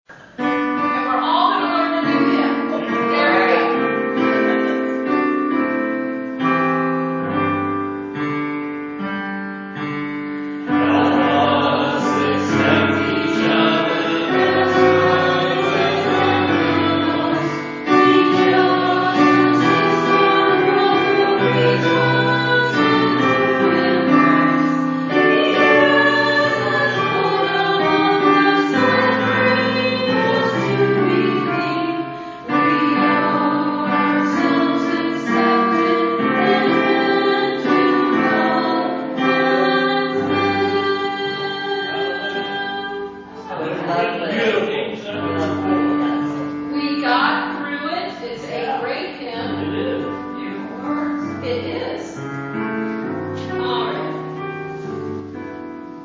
Hymn Sing